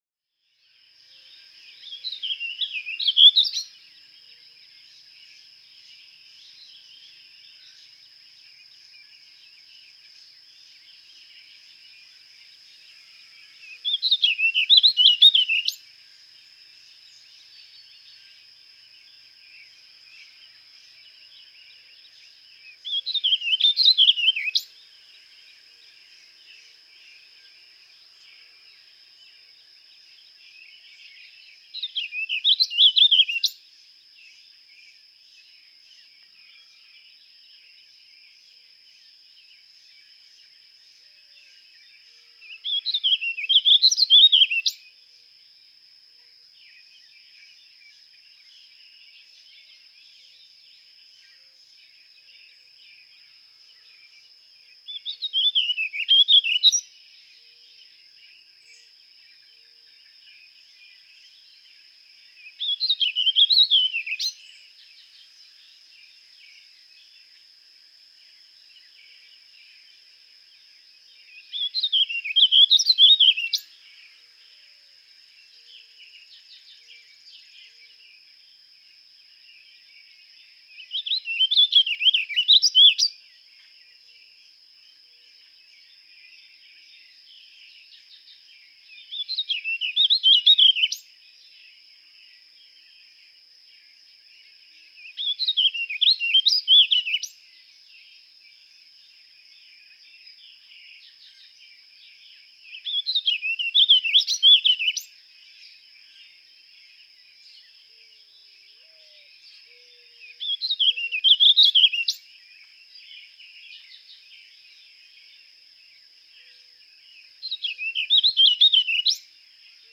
Warbling vireo
♫162. Adult song, among eastern birds heard (by some) as If I SEES you, I will SEIZE you, and I'll SQUEEZE you till you SQUIRT!.
162_Warbling_Vireo.mp3